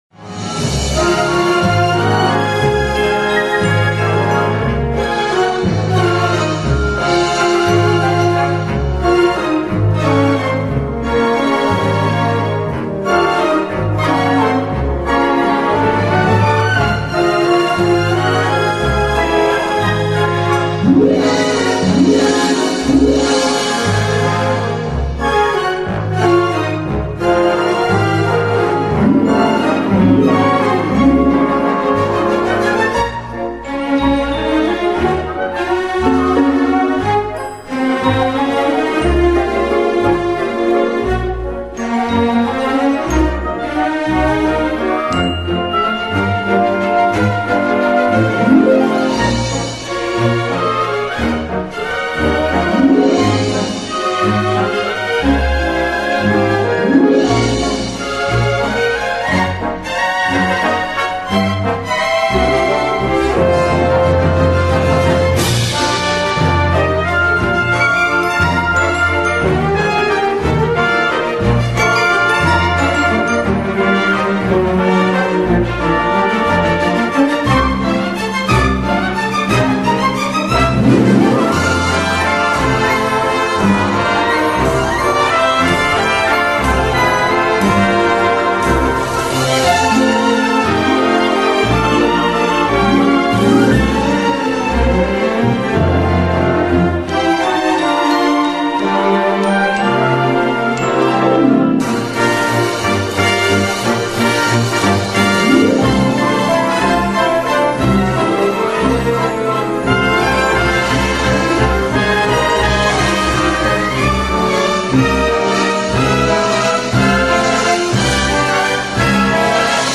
with beautiful orchestration!